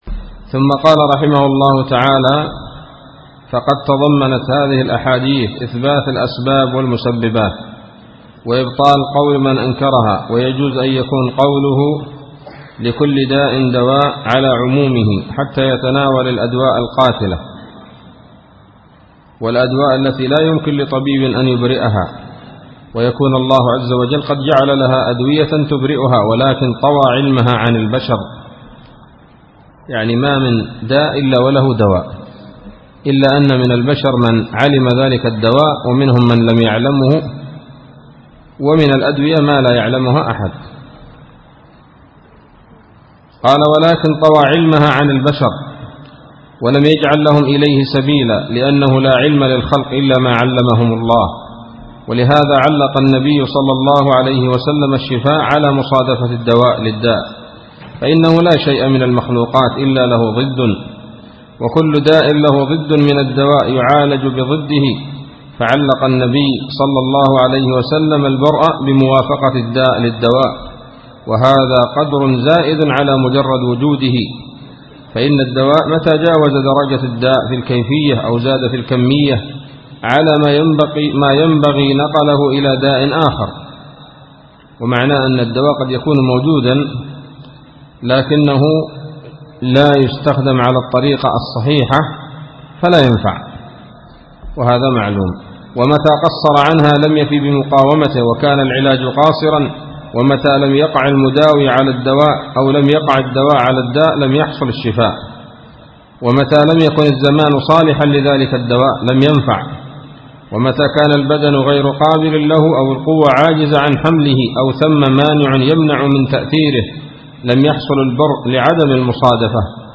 الدرس الرابع من كتاب الطب النبوي لابن القيم